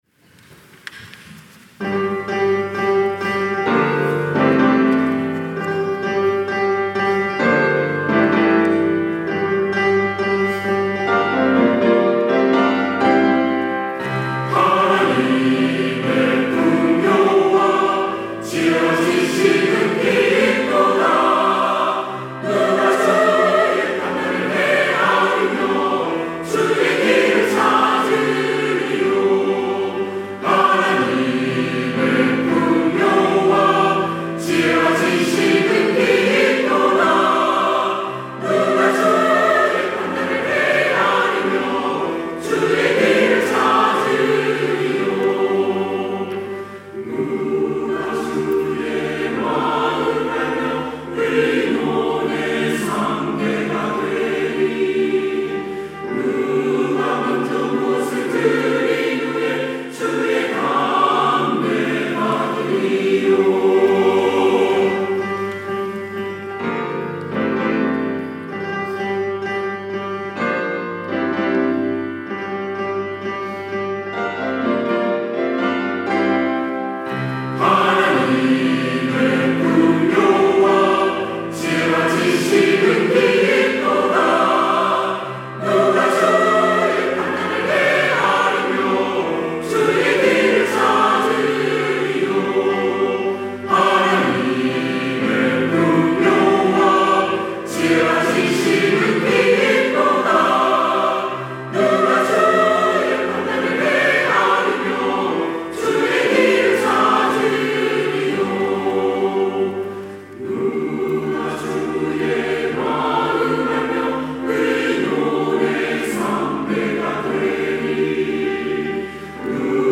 시온(주일1부) - 주님께 영광을 영원히
찬양대